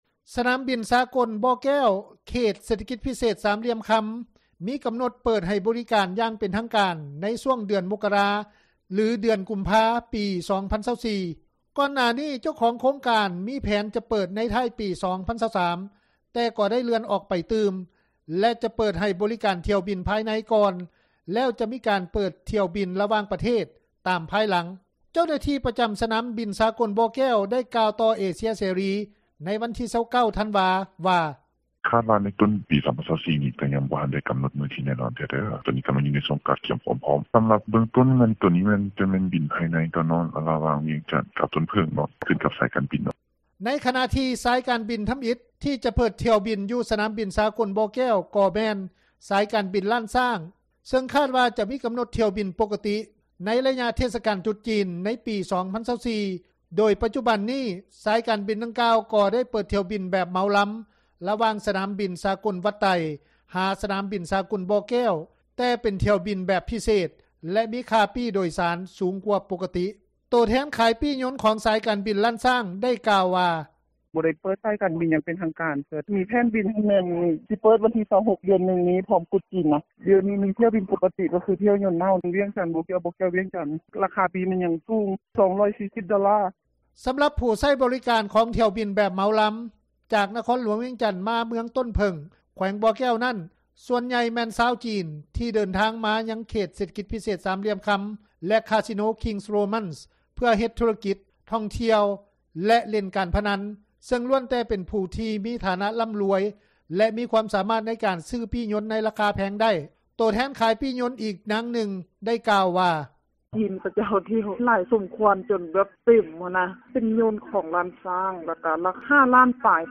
ໂຕແທນຂາຍປີ້ຍົນ ອີກນາງນຶ່ງ ໄດ້ກ່າວວ່າ:
ຜູ້ປະກອບການນໍາທ່ຽວ ຢູ່ນະຄອນຫຼວງວຽງຈັນ ທ່ານນຶ່ງ ໄດ້ກ່າວວ່າ: